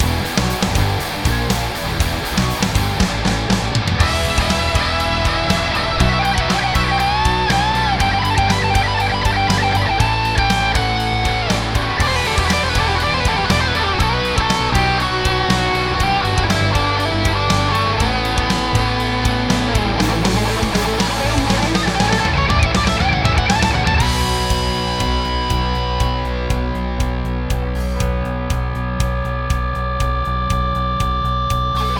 Its a similar tone to the rhythm, possibly with a little more gain. View attachment Lead Tone Test.mp3 Yes, I know my playing is still a little flakey on this - but I've done loads of takes and keep making tiny mistakes! All I've done in the DAW is add a bit of reverb.